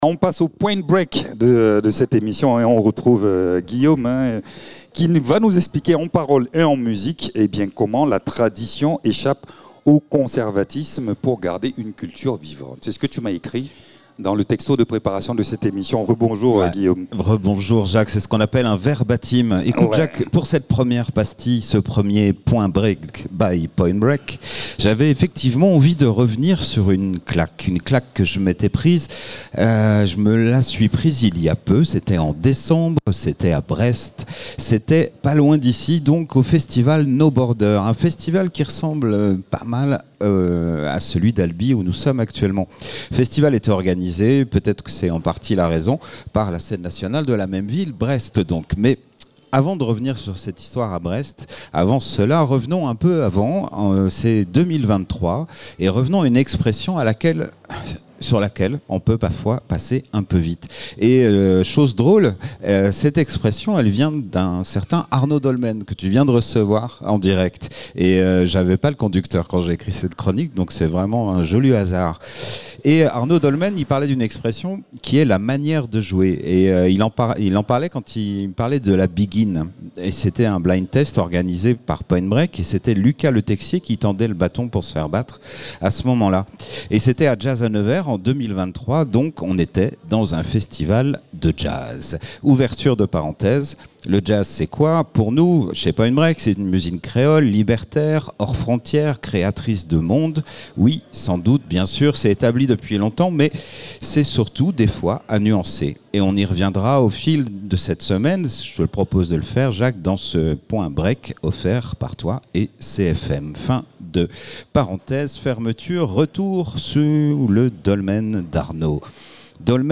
en direct du Albi Jazz Festival